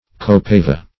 Copaiba \Co*pai"ba\ (?; 277), Copaiva \Co*pai"va\, n. [Sp.